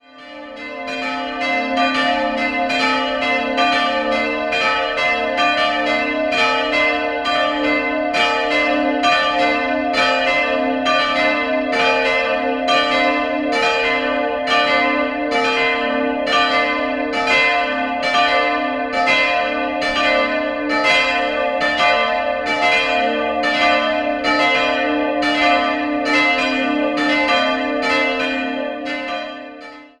1714 erfolgte ein Neubau, den man 1927 verlängerte. 3-stimmiges Geläut: a'-c''-d''